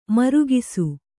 ♪ marugisu